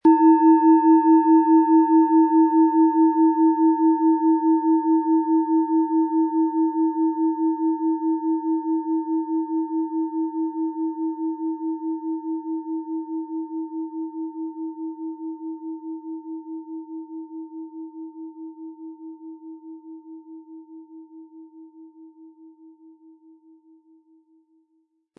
Die Planetenklangschale Alphawelle ist von Hand gefertigt worden.
Sanftes Anspielen mit dem gratis Klöppel zaubert aus Ihrer Schale berührende Klänge.
SchalenformBihar
MaterialBronze